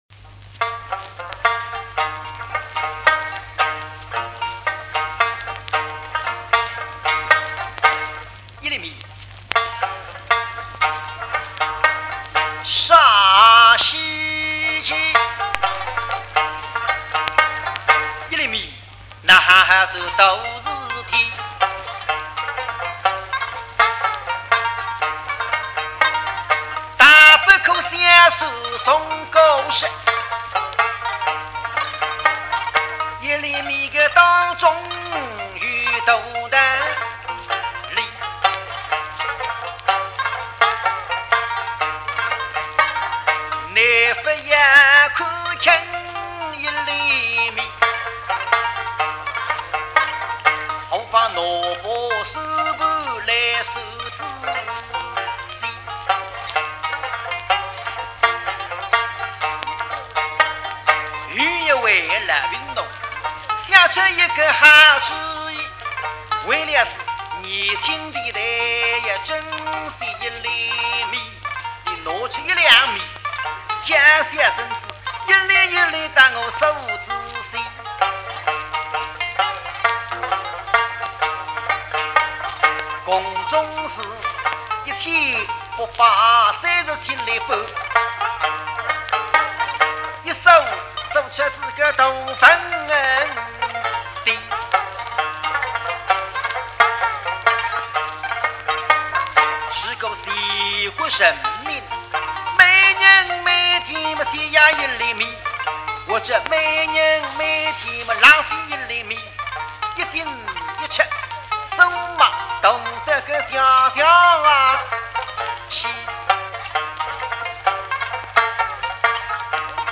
很簡單的一個唱段，節奏明快，是「快徐調」的延伸，很適合作為「第一隻開篇」來聽。